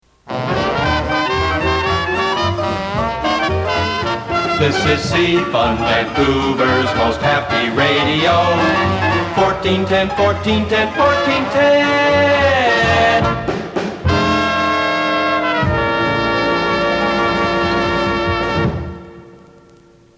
JINGLES FROM EARLY 'FUNLAND' RADIO